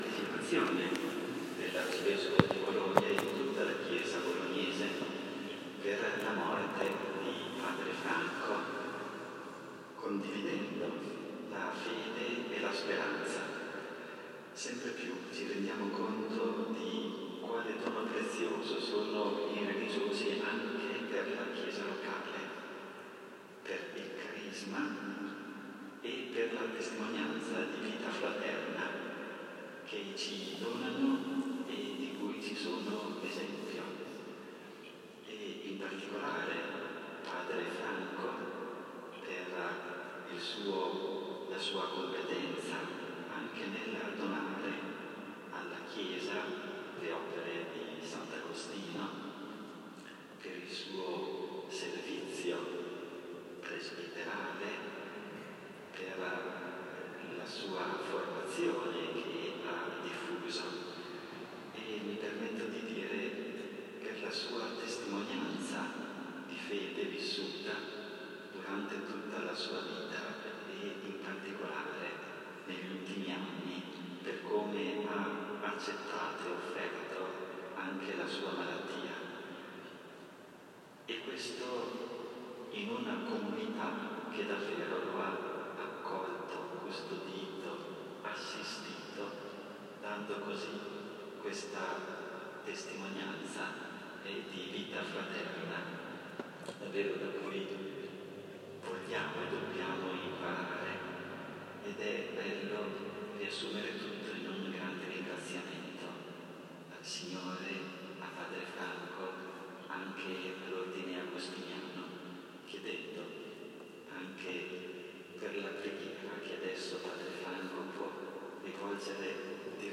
Clic col tasto destro per scaricare Basilica di San Giacomo Maggiore Ringraziamenti
Basilica-di-San-Giacomo-Maggiore-Ringraziamenti.m4a